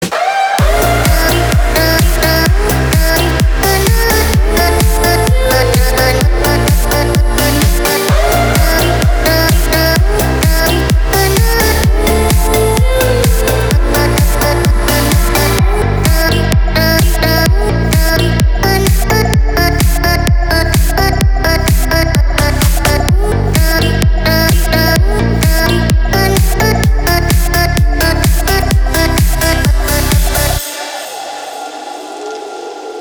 ритмичные
громкие
веселые
заводные
dance
progressive house
звонкие
Заводной ритмичный рингтон
Progressive House